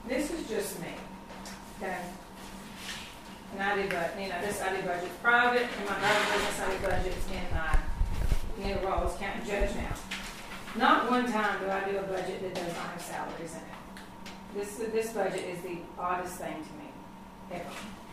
The meeting, held at the communications center in Glasgow, featured a review of the proposed 2024-25 budget and a contentious debate over the redrawing of the counties’ interlocal agreement.
Barren County Judge-Executive Jamie Bewley-Byrd raised significant concerns about the absence of a line item for salaries in the proposed budget.